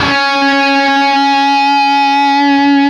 LEAD C#3 CUT.wav